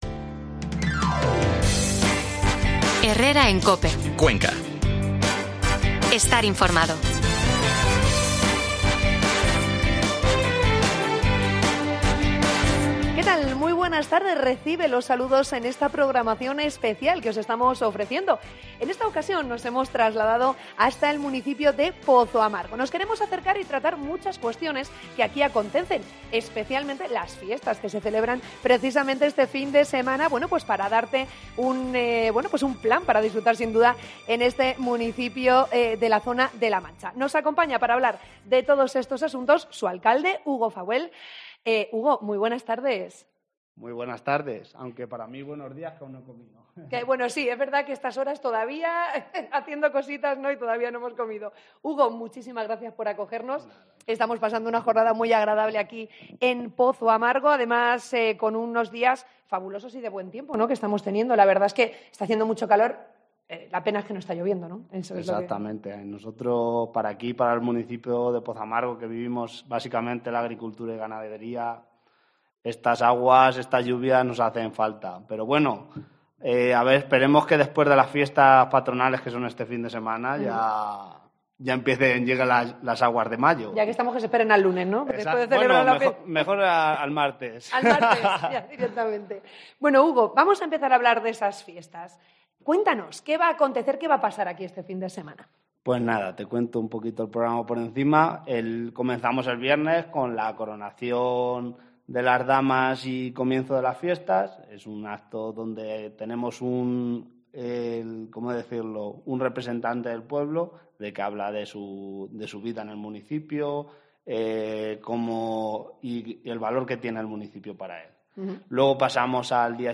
AUDIO: Programa especial de COPE Cuena desde Pozoamargo